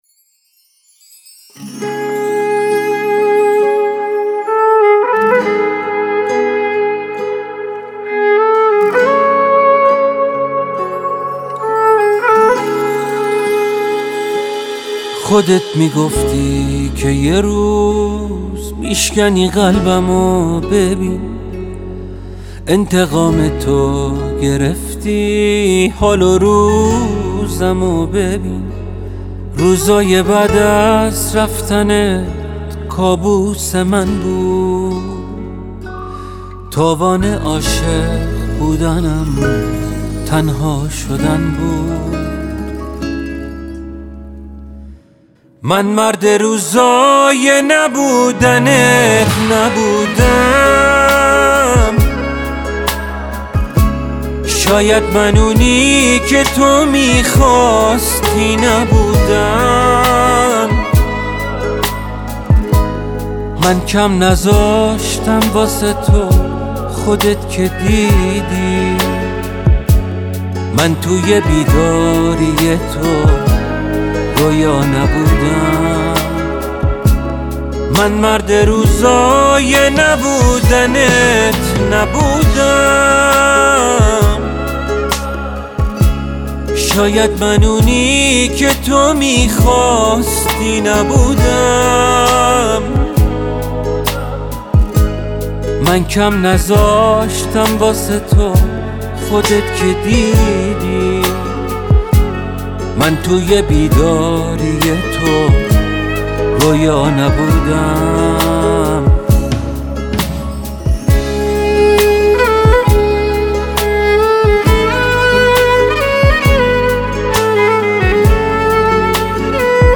ژانر: پاپ